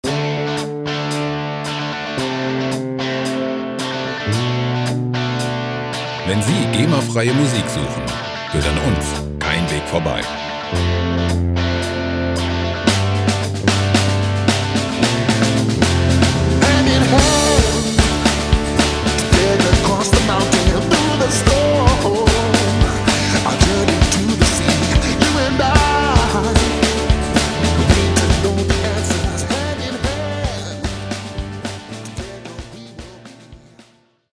freie Rock Loops
Musikstil: Pop Rock
Tempo: 112 bpm